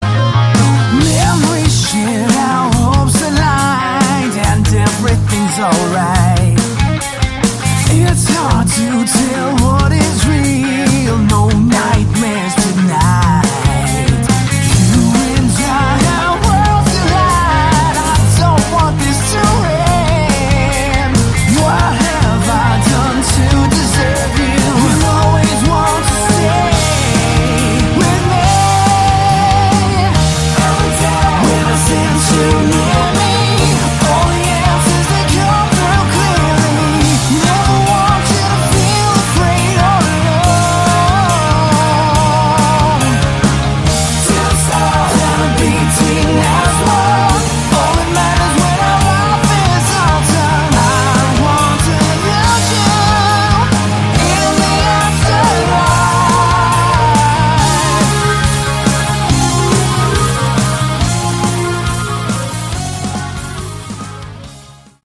Category: Melodic Rock
lead guitars, vocals
drums
keyboards, vocals
bass, vocals